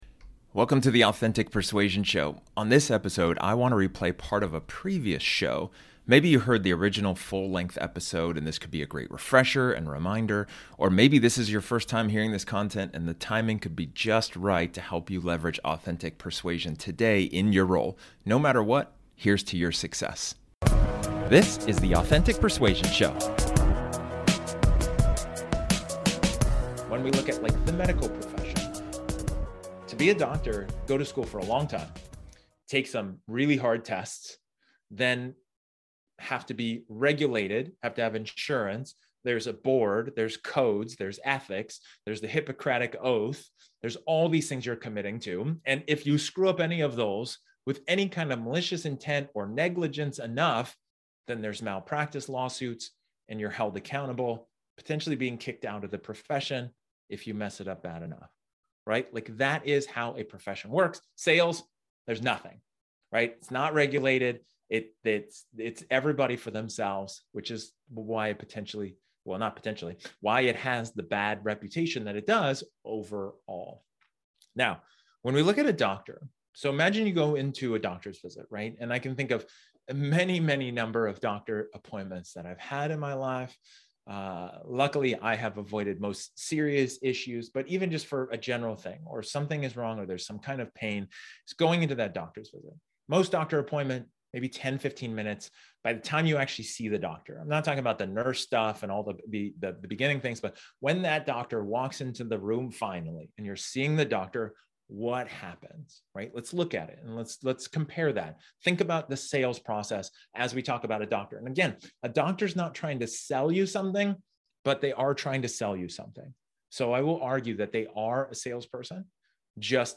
This episode is an excerpt from one of my training sessions where I talk about effective empathy.